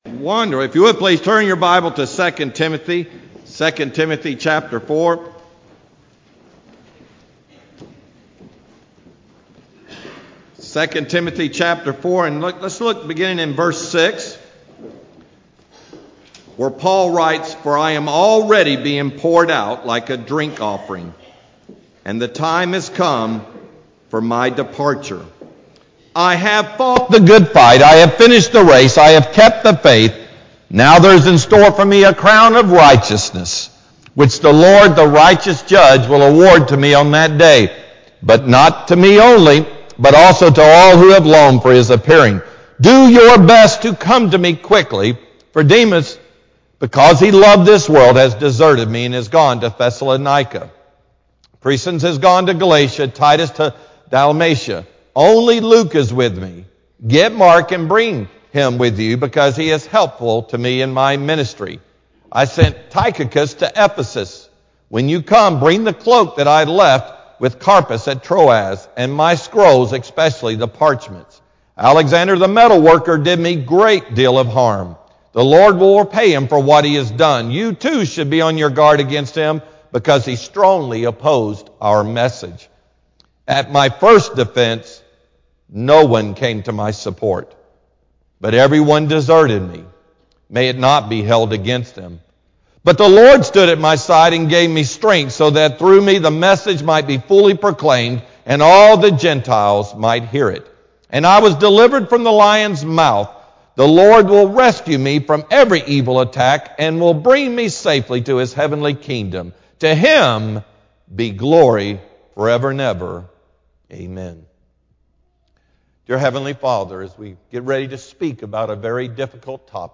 Sermon-8-15-PM-CD.mp3